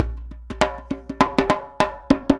100 JEMBE5.wav